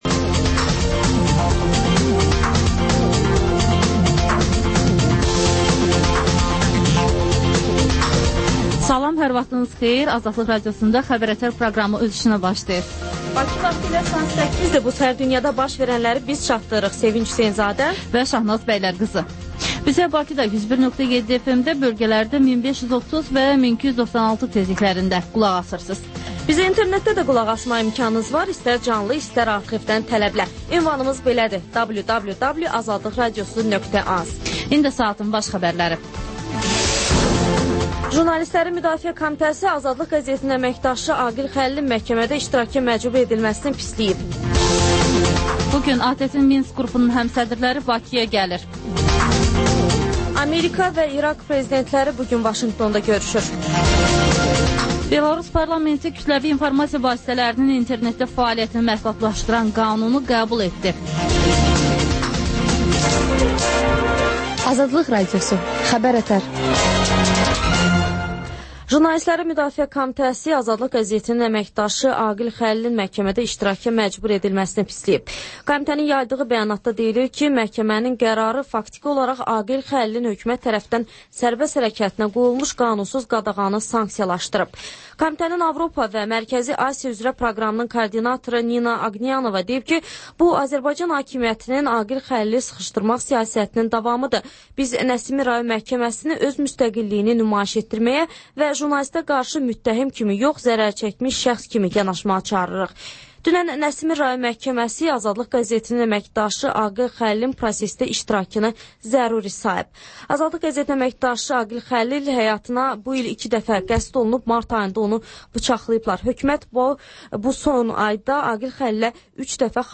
Xəbər-ətər: xəbərlər, müsahibələr və ŞƏFFAFLIQ: Korrupsiya haqqında xüsusi veriliş